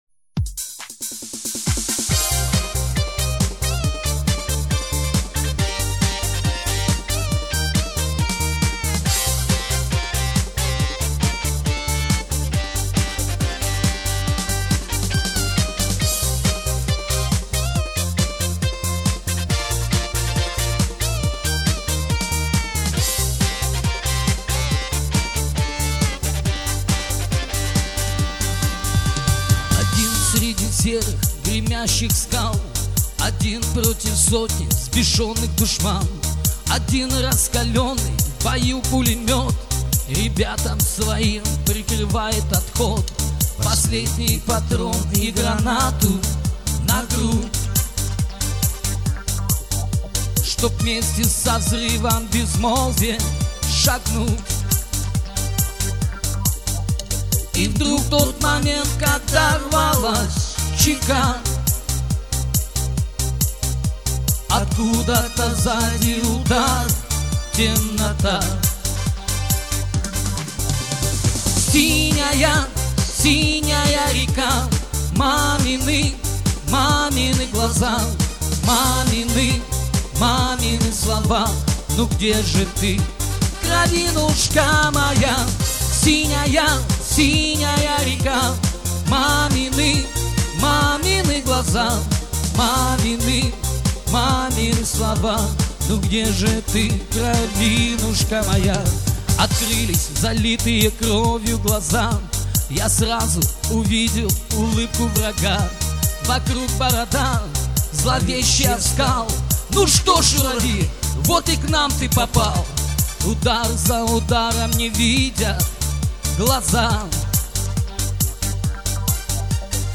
Уличные музыканты - "Афганцы" - Дембельский альбом (Название условное)